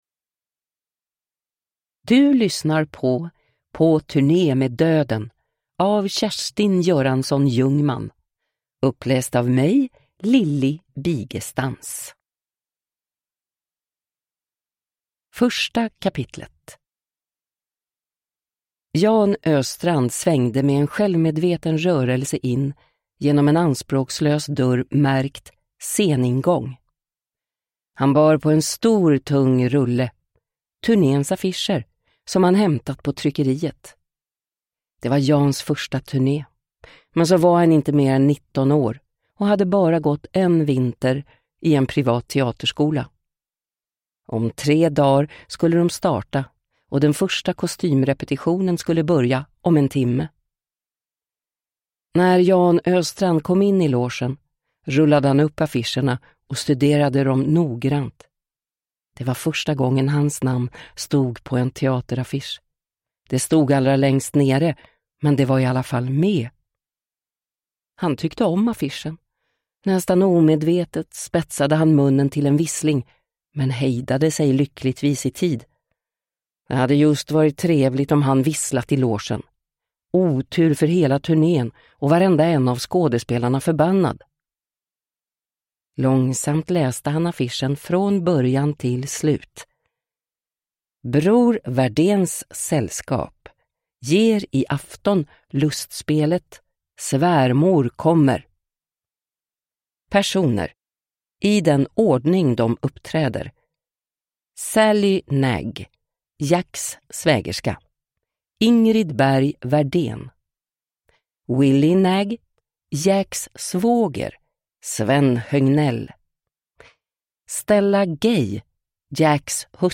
På turné med döden (ljudbok) av Kjerstin Göransson-Ljungman